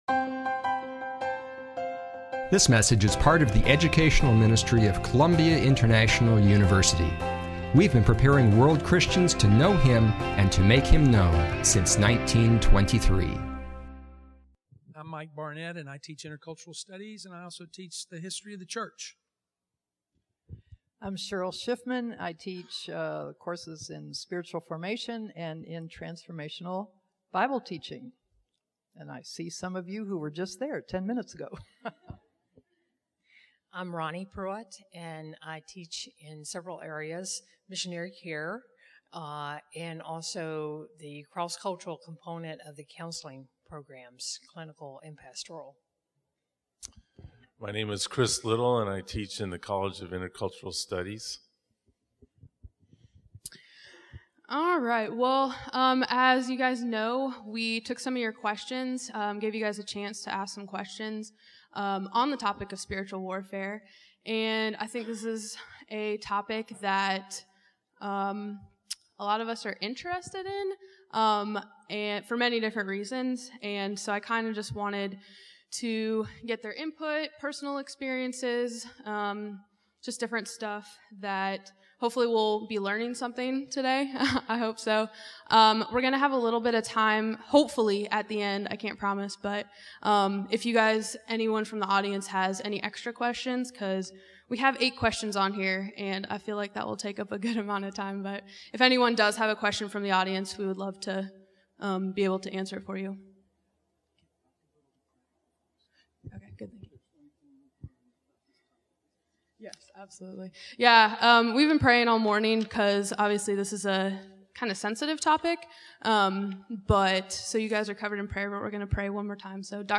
11/05/2014-Grad Life Chapel's Panel Discussion on Spiritual Warfare
grad-life-chapels-panel-discussion-on-spiritual-warfare.mp3